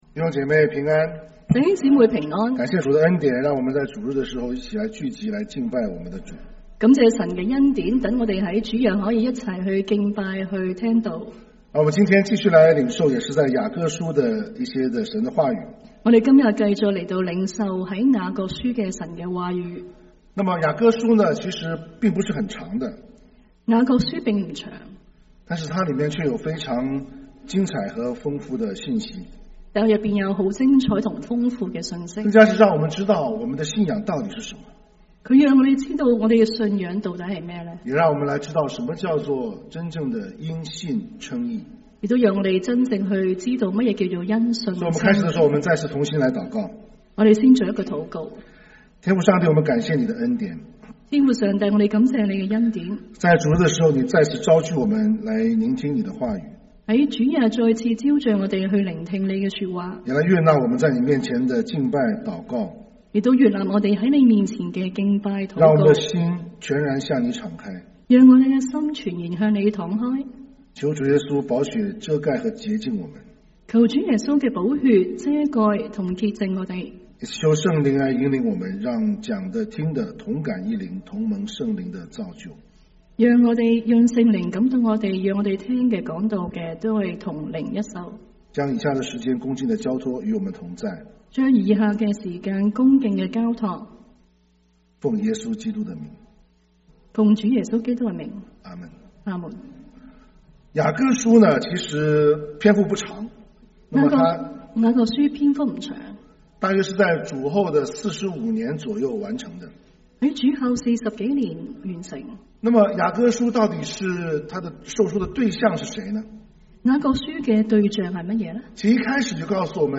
2/16/2025 國粵語聯合崇拜: 「挽回」